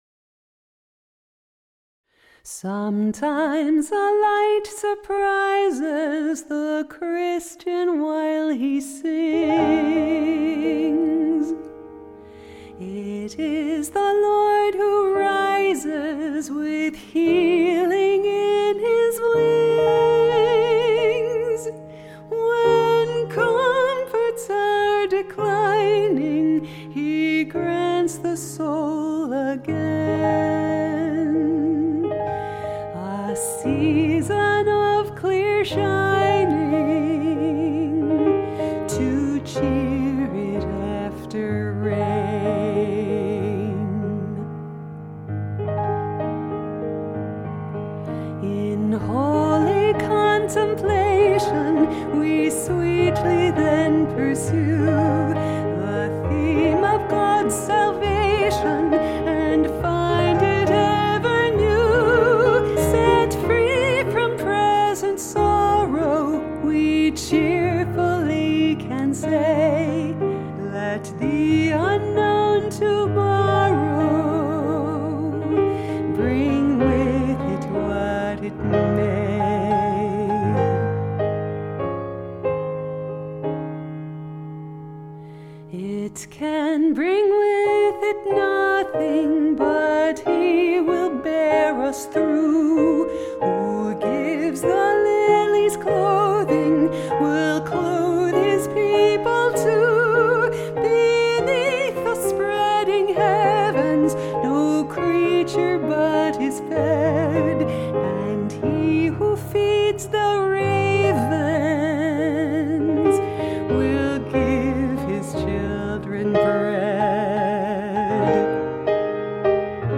Latest Solos
Sometimes a Light Surprises - Cowper/Courtney 2/12/25 Eagleton